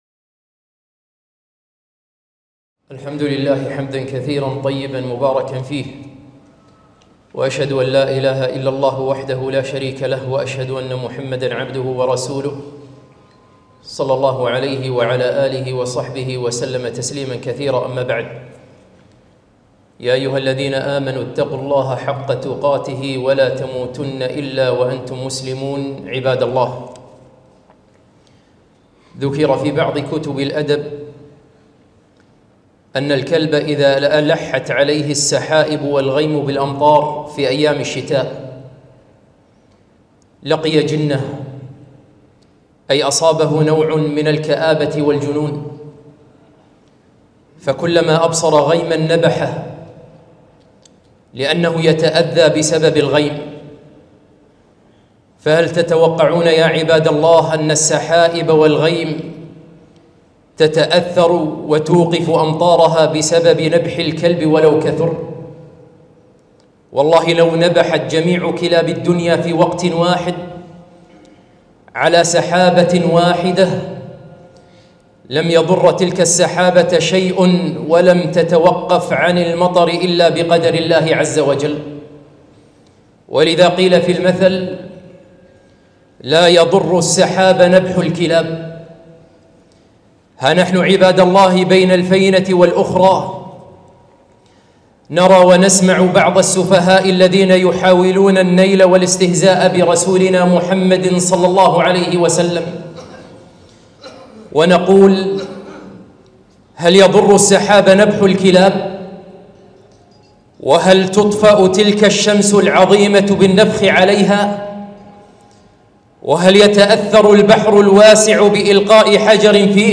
خطبة - لا يضر السحابَ نبحُ الكلاب